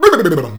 B-B-B-B-OM.wav